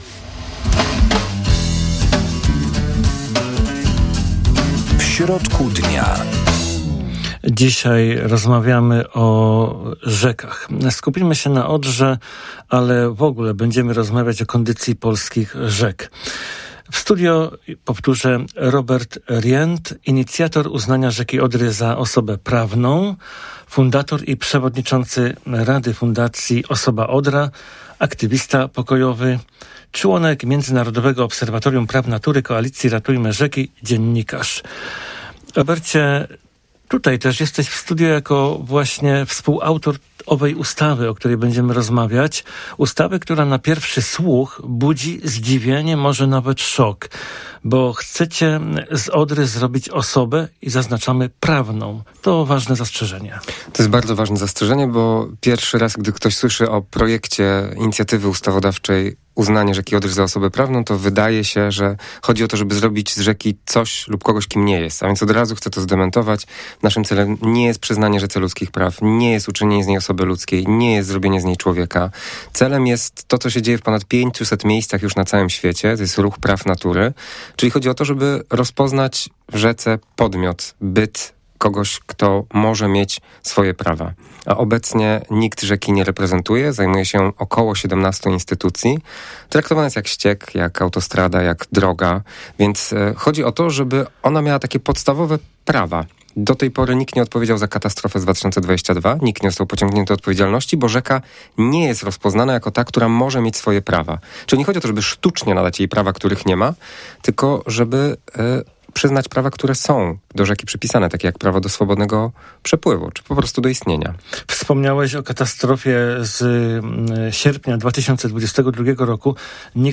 Zapraszamy do rozmowy w audycji „W środku dnia”.